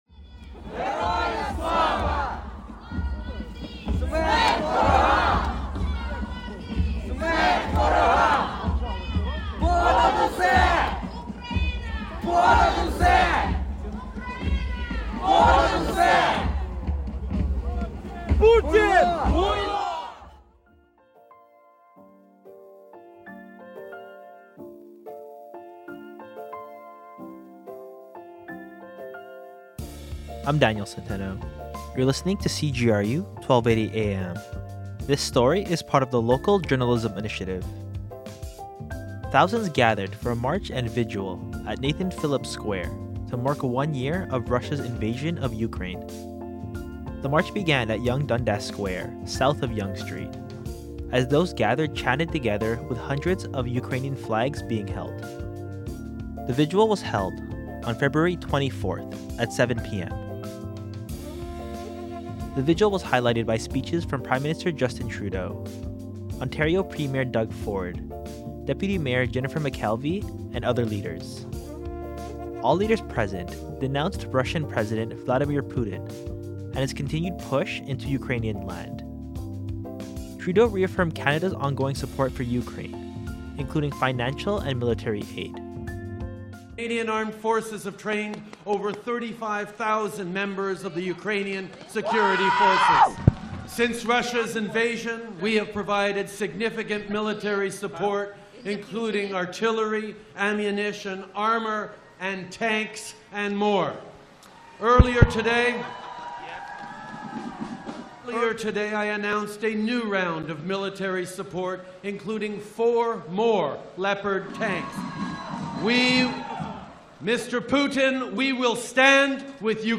In a show of both solidarity and remembrance, thousands gathered for a march and vigil in the downtown core to mark one year since Russia's invasion of Ukraine began.
Hundreds of Ukrainian flags were held as the crowd chanted "Slava Ukraini" (Glory to Ukraine).